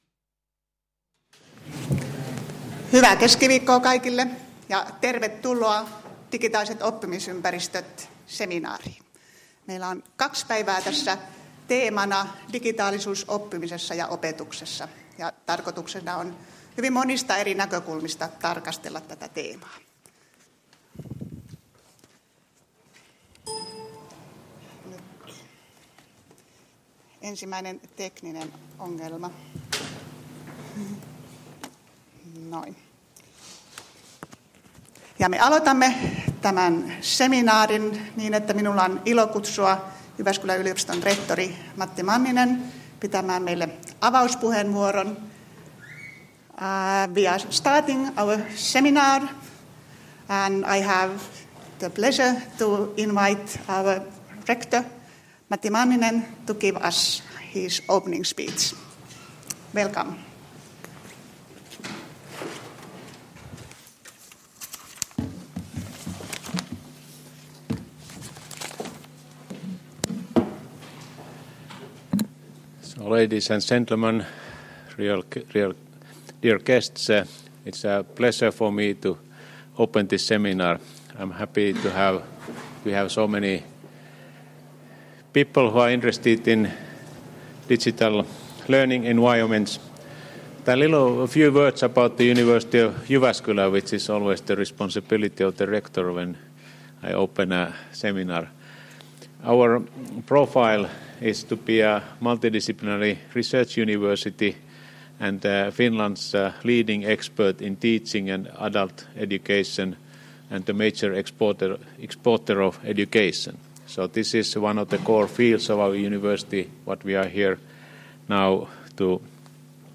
Ohjelma koostuu lyhyistä puheenvuoroista, paneelikeskusteluista, EduFestistä, postereista ja työpajoista.